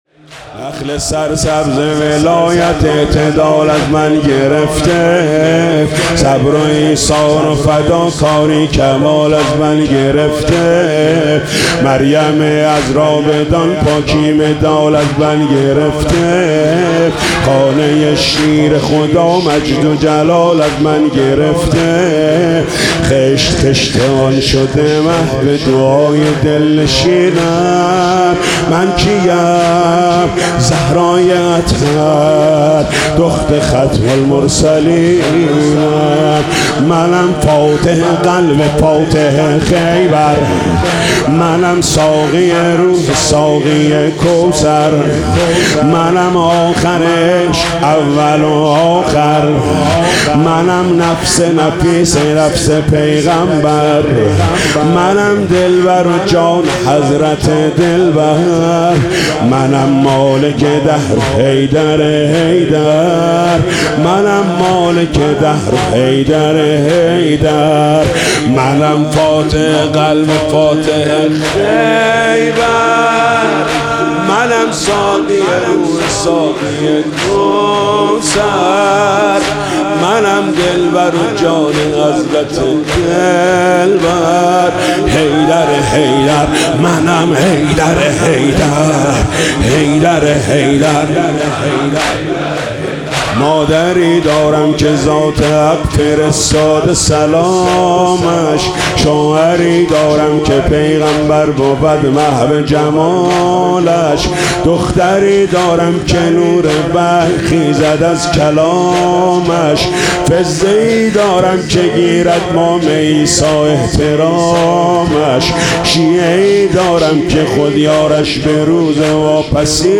نخل سرسبز ولایت اعتدال از من گرفته - زمینه شب پنجم فاطمیه 1403